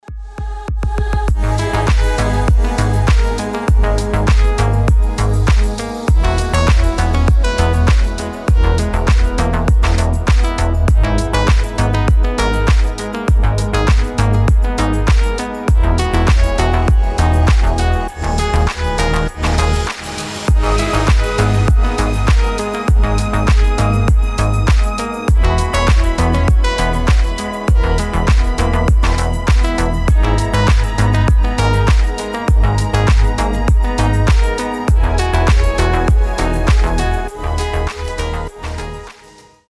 deep house
Electronic
спокойные
без слов
медленные
Стильная музыка жанра дип хаус